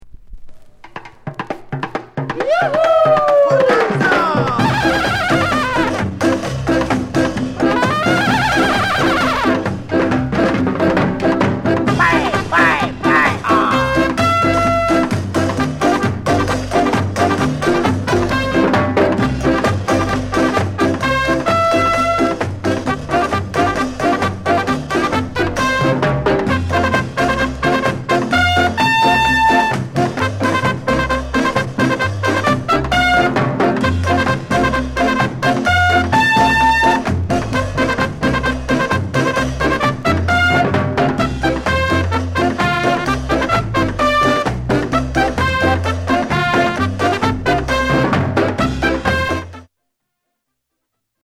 NICE SKA INST